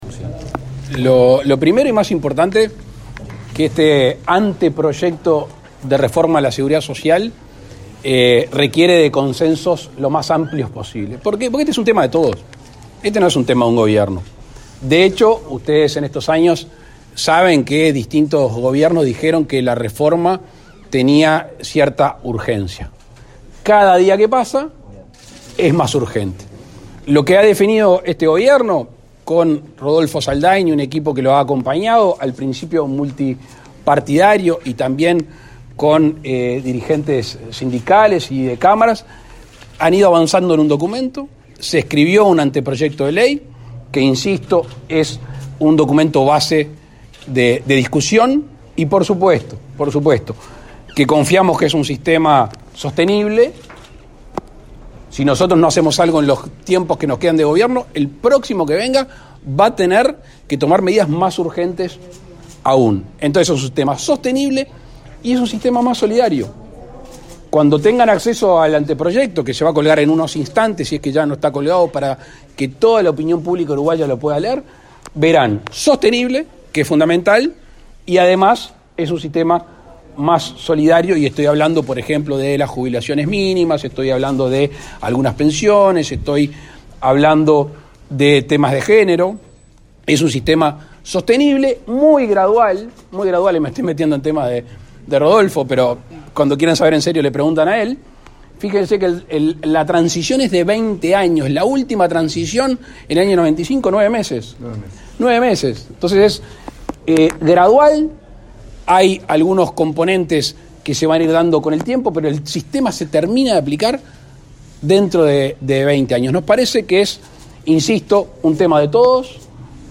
Declaraciones del presidente Lacalle Pou a la prensa
Declaraciones del presidente Lacalle Pou a la prensa 29/07/2022 Compartir Facebook X Copiar enlace WhatsApp LinkedIn El presidente Luis Lacalle Pou se reunió con los principales dirigentes del Frente Amplio, en la sede de ese partido político, para entregarles el anteproyecto de ley de reforma de la seguridad social. Luego, dialogó con la prensa.